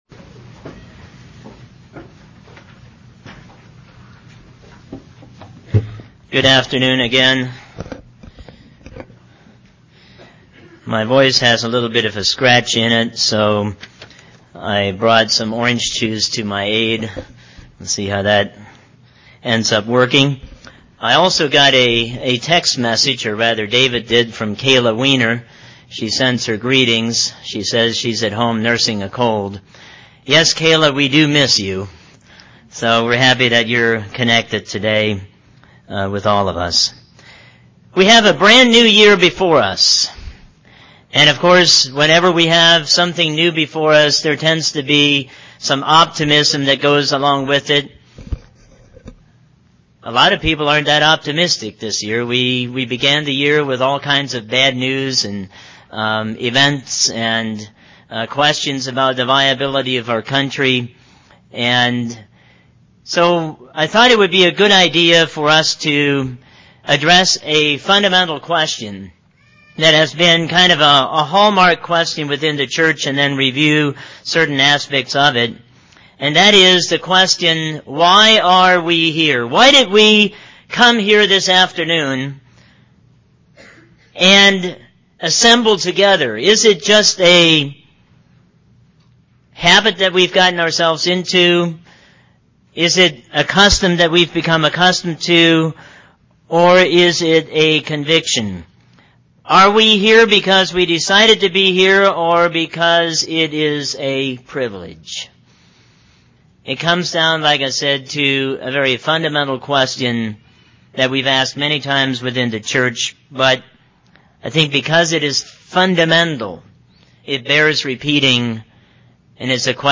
Given in North Canton, OH
UCG Sermon Studying the bible?